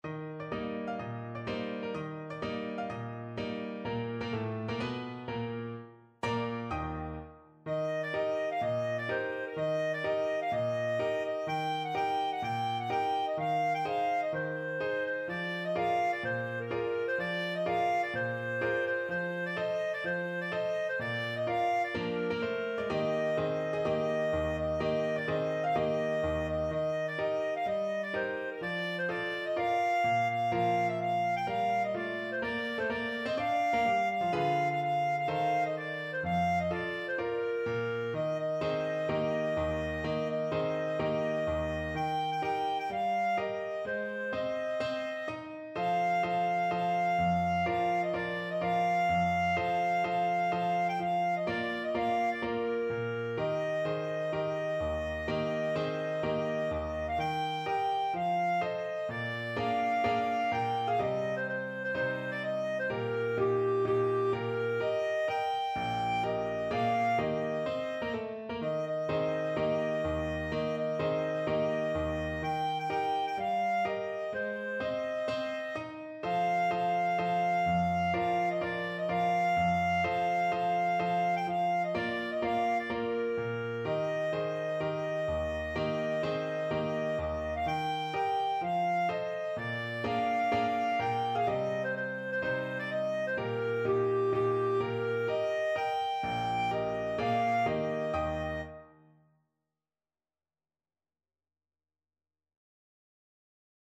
Moderato =126
4/4 (View more 4/4 Music)
Classical (View more Classical Clarinet Music)